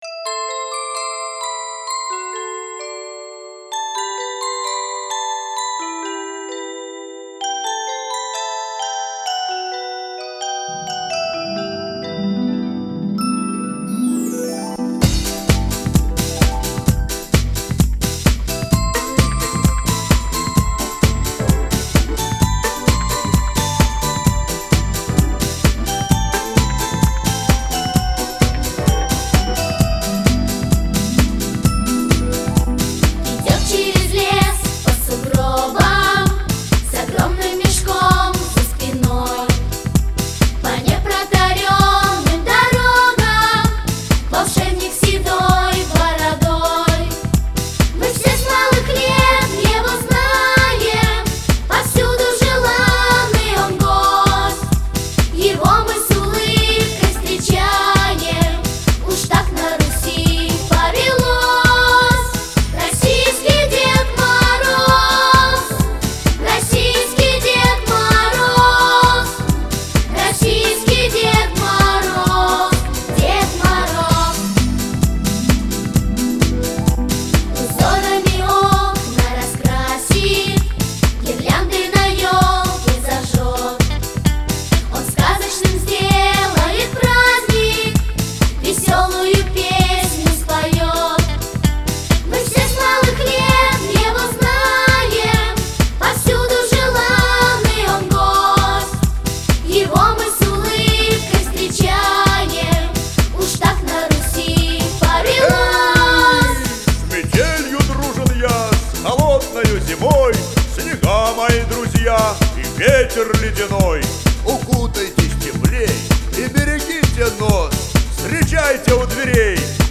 Rossiqskiq_ded_moroz_detskaya_pesenka.mp3